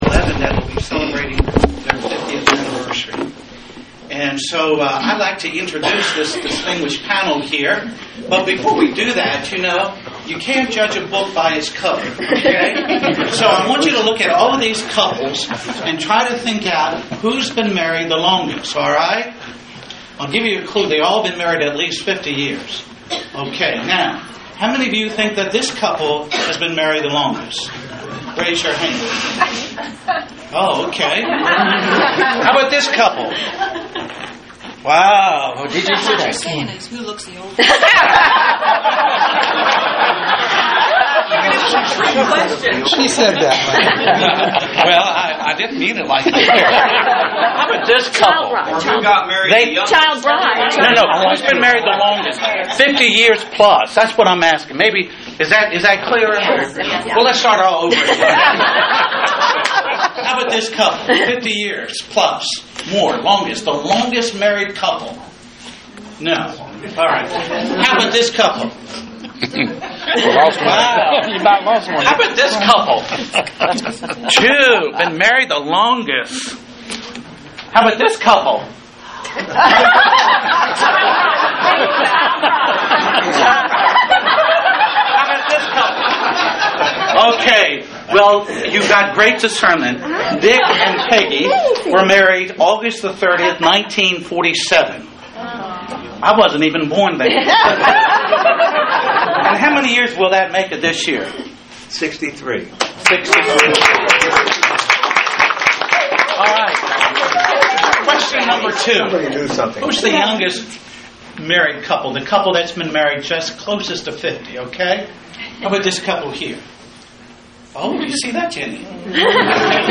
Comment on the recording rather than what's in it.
This past Saturday, we had what we called a “Christian Living Seminar” and for our first seminar we highlighted 5 couples in our church (among approximately 35!) who had been married over 50 years.